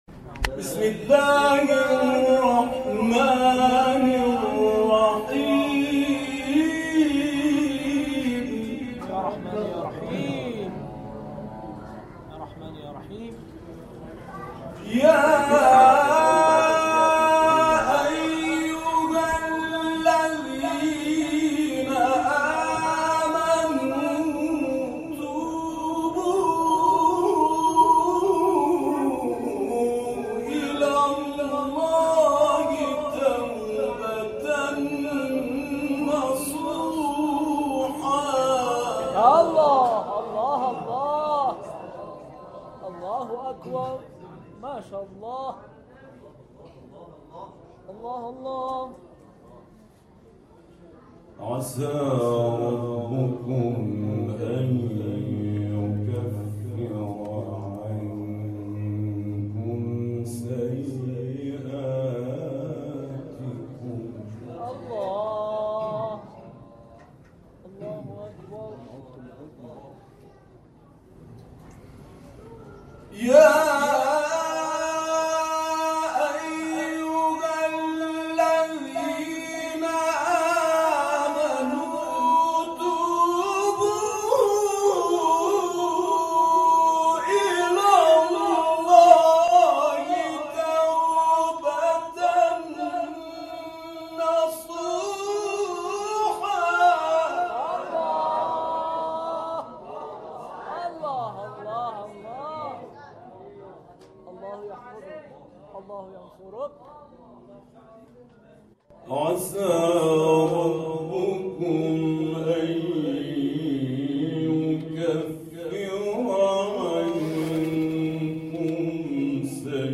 این جلسه معنوی با حضور اساتید و قاریان ممتاز و بین المللی استان‌های لرستان و همدان، هفته گذشته در آستان امامزاده عبدالله(ع) شهر همدان برگزار شد.